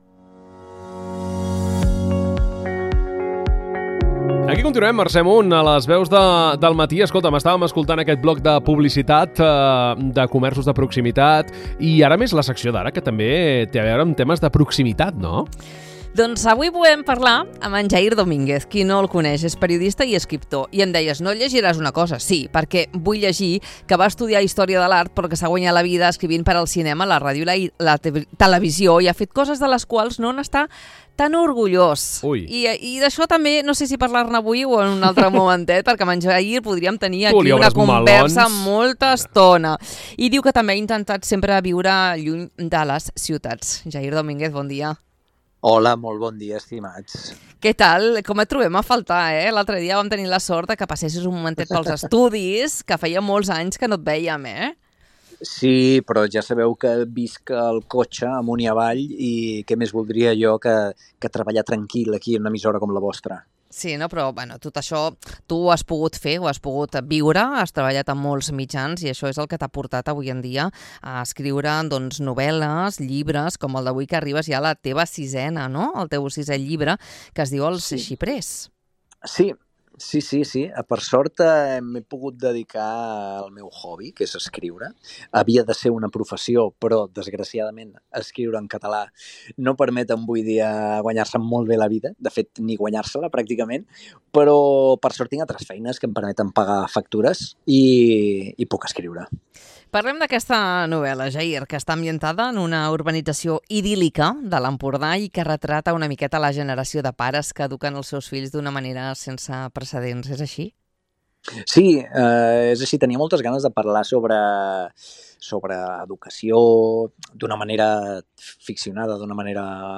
LVDM - ENTREVISTA - JAIR DOMINGUEZ 12 MARÇ 25~0.mp3